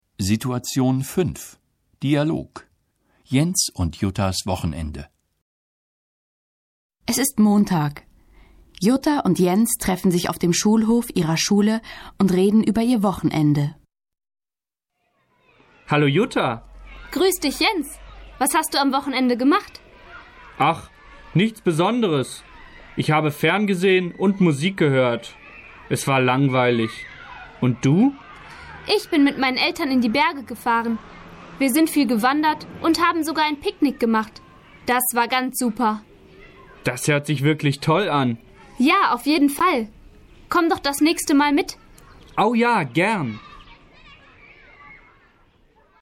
Situation 5 – Dialog: Jens' und Juttas Wochenende (736.0K)